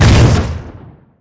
mega_bouncehard3.wav